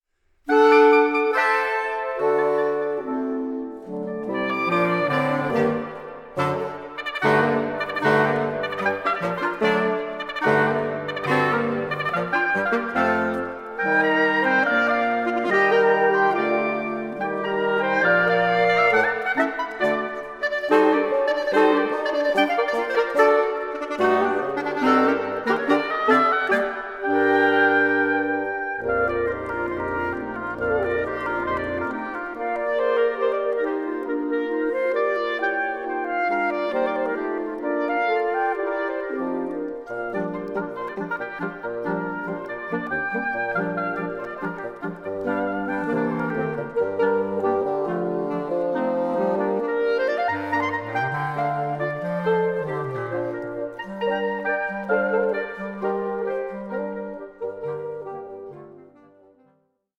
Allegretto grazioso 2:53
reed players